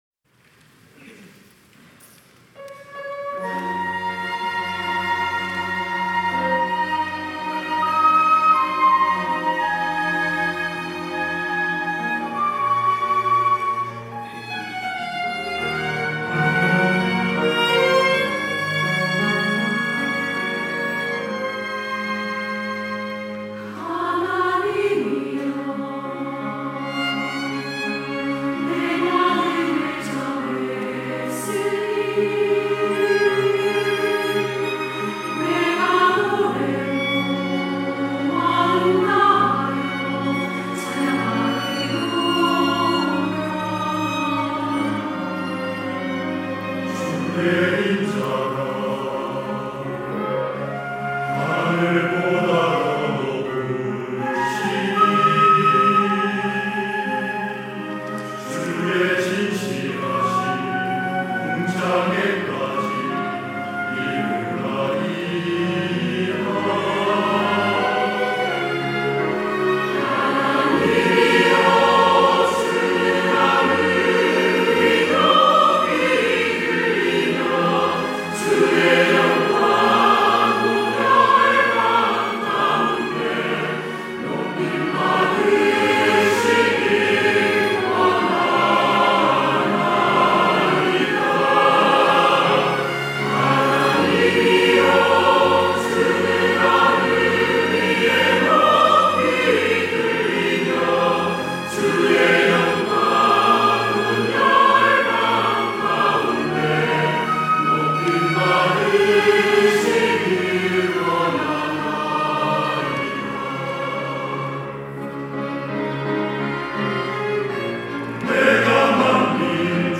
할렐루야(주일2부) - 시편 108편
찬양대